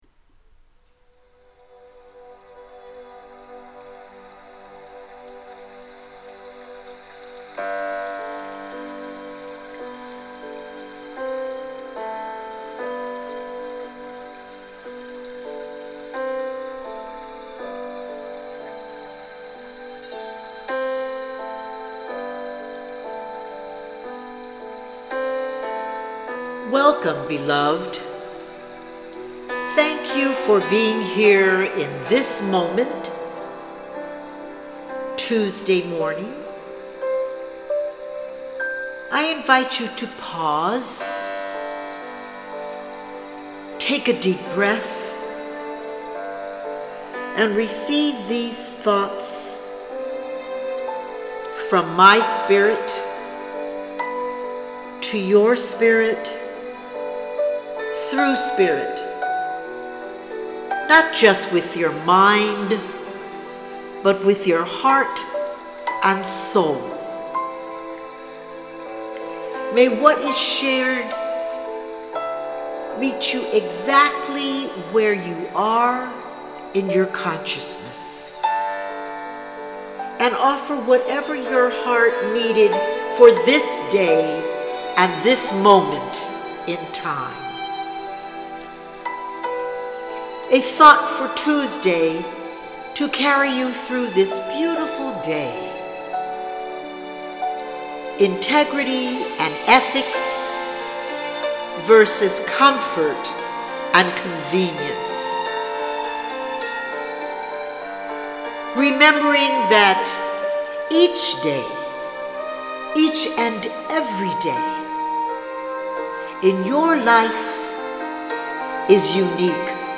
Thank You Jim Brickman for your beautiful music that vibrates through this recording.